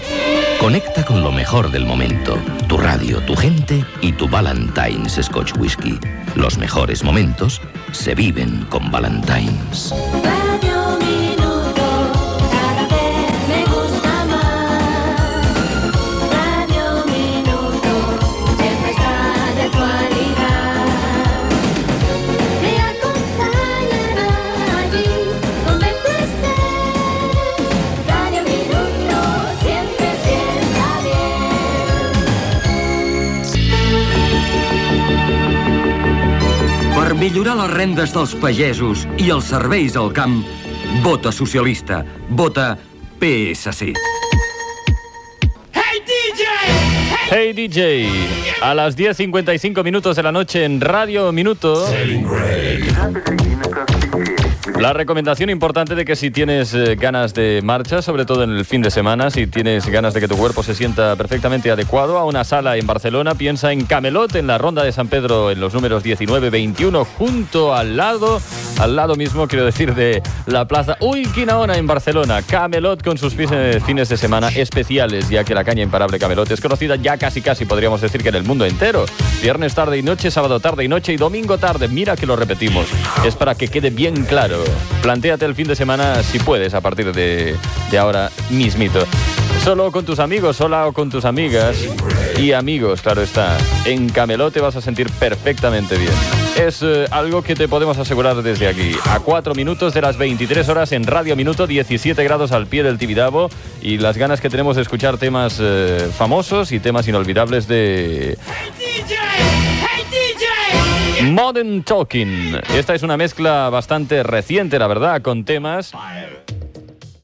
Publicitat, indicatiu de la ràdio, propaganda electoral del Partit Socialista de Catalunya, hora, identificació, publicitat, hora, temperatura i tema musical
Musical
FM